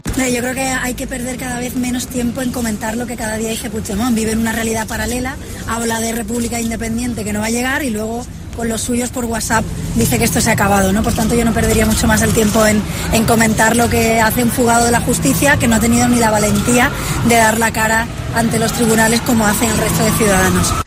En declaraciones a los medios durante su participación en un acto con motivo del Día de Andalucía, Arrimadas ha censurado la decisión de Torrent y de Colau de no asistir a la recepción oficial que ofrecerá hoy Felipe VI con motivo del Mobile World Congress y las afirmaciones de Puigdemont, en las que dijo que el monarca "será bienvenido a la 'república' de Cataluña cuando pida perdón por su papel inconstitucional el pasado mes de octubre".